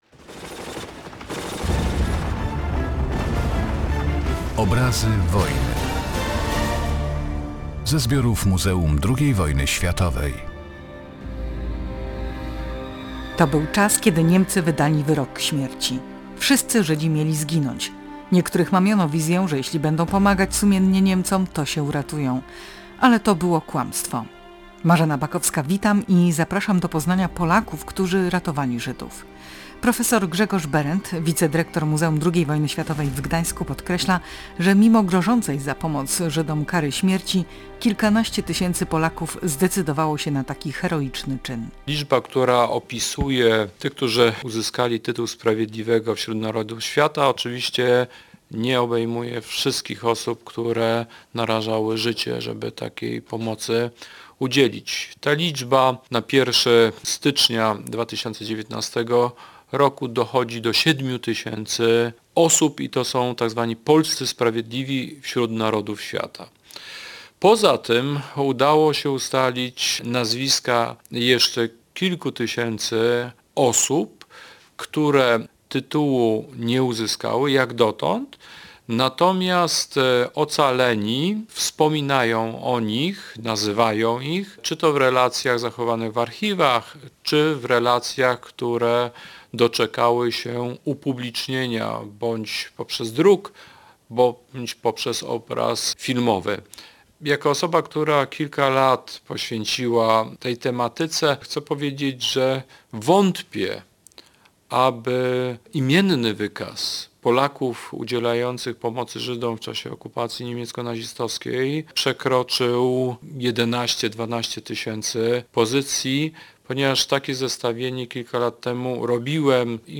W audycji głos zabierają także świadkowie.